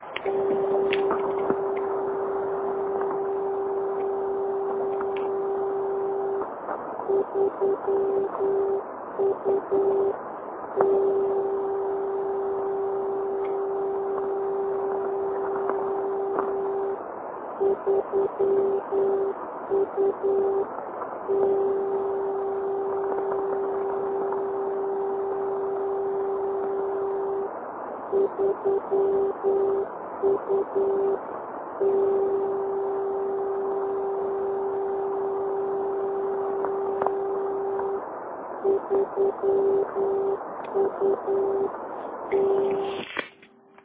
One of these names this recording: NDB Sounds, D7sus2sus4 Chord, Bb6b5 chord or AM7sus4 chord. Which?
NDB Sounds